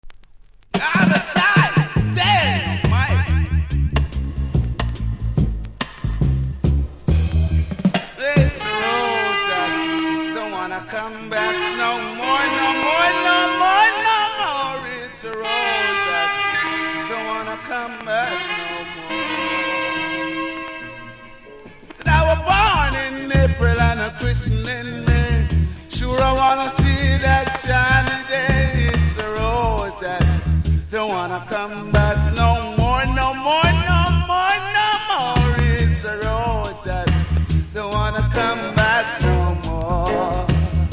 REGGAE 70'S
うすキズ多々ありますが音は良好なので試聴で確認下さい。